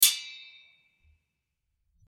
Swords Clashing #1 | TLIU Studios
Category: Impact Mood: Action-Packed Editor's Choice